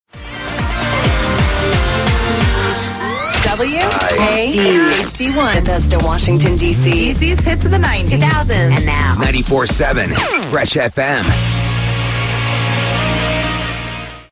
WIAD Top of the Hour Audio: